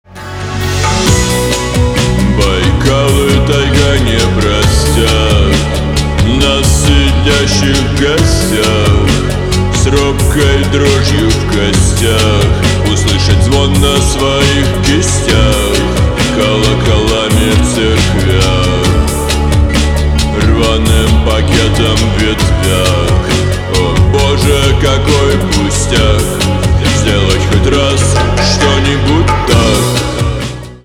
пост-панк
гитара , барабаны , грустные , чувственные